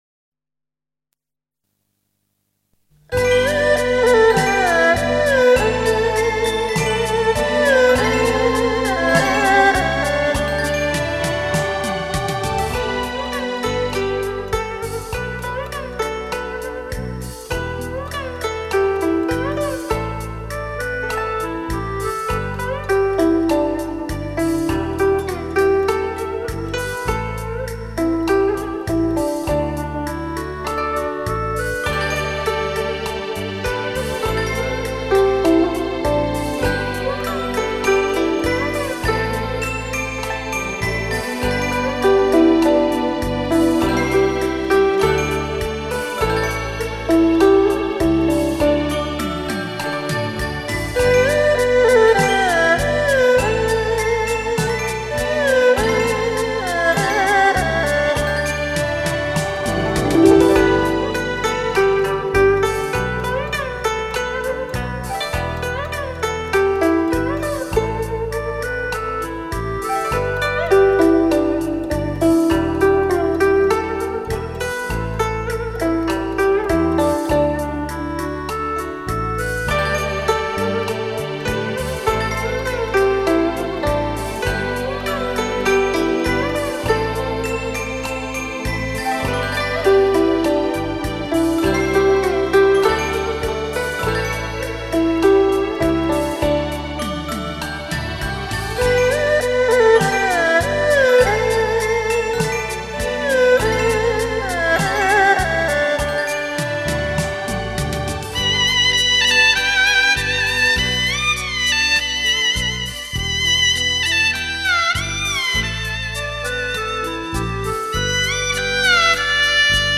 有轻松动听、脍炙人口的民歌精选、有旋律优美，婉转动人的柔情乐曲、有悠扬缠绵、回味难忘的影视插曲
一段乐曲足已把人引到一个世外桃源,尽享民族风情,如乘风驾云赏月,沁人心脾.